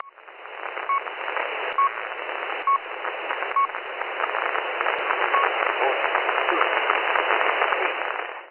RX: LOWE HF-150
antenna: HiQBBA